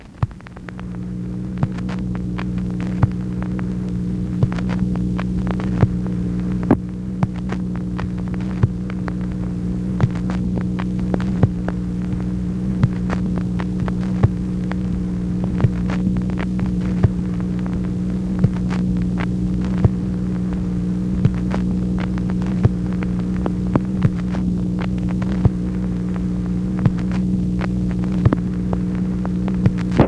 MACHINE NOISE, January 22, 1964
Secret White House Tapes | Lyndon B. Johnson Presidency